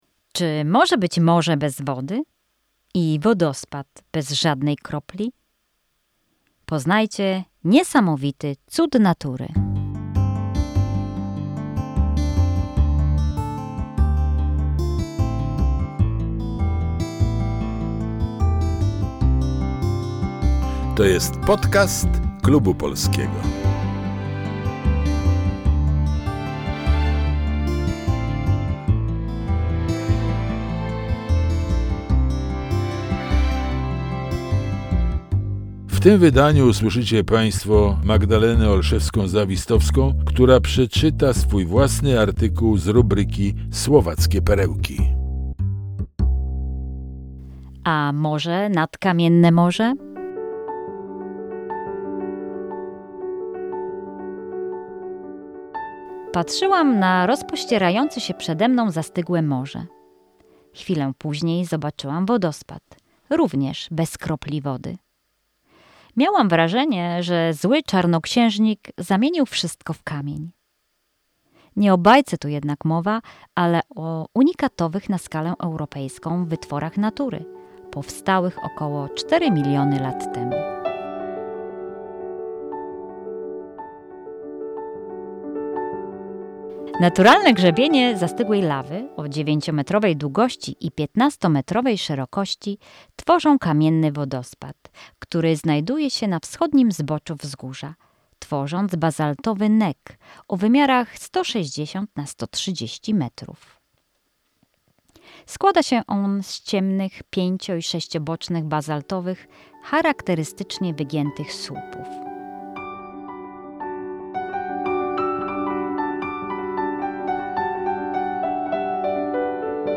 Muzyka: Asher Fulero – Swans In Flight